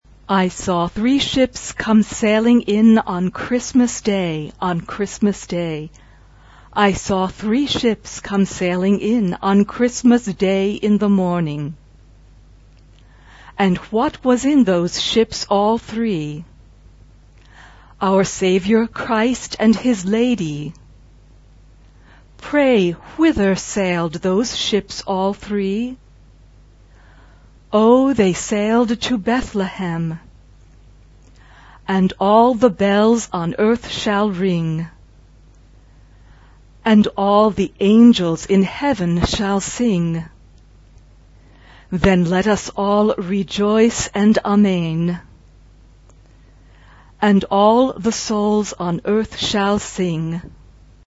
Tonalité : fa majeur